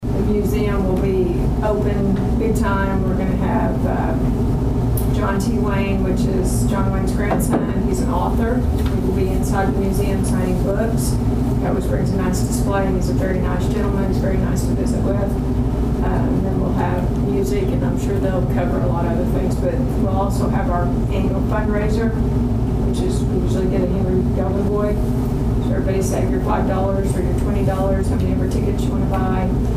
The Dewey City Council met for the first time in the month of September on Tuesday night at Dewey City Hall.